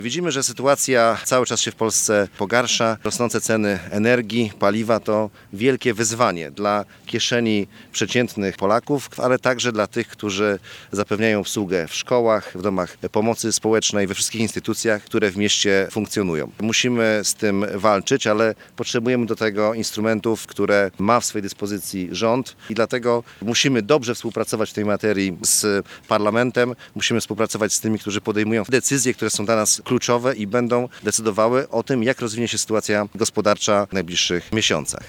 Z Prezydentem Piotrem Krzystkiem wziął udział w konferencji prasowej zorganizowanej na Jasnych Błoniach. Krzystek mówił o ważnej roli współpracy samorządu z parlamentarzystami.